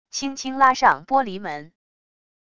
轻轻拉上玻璃门wav下载